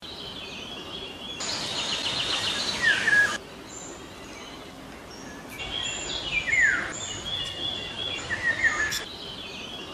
Waldgeräusch 6: Waldvogel und Waldvögel / forest sound 6: forest bird and forest birds